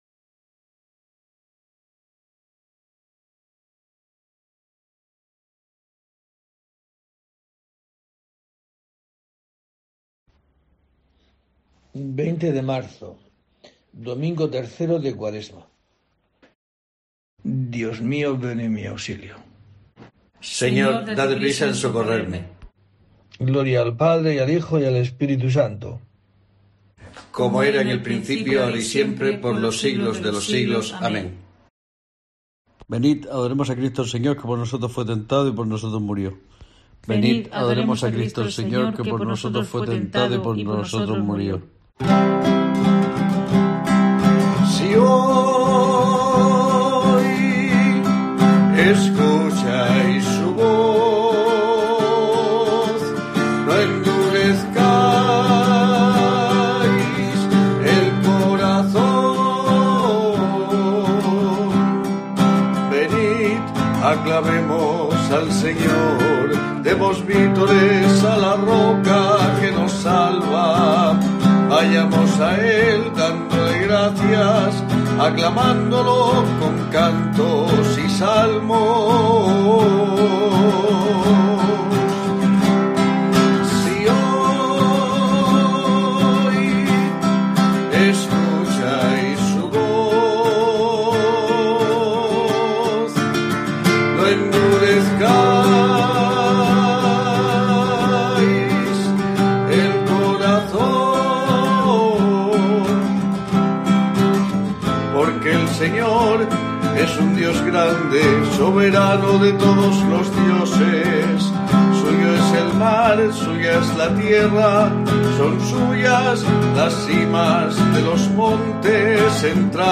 20 de marzo: COPE te trae el rezo diario de los Laudes para acompañarte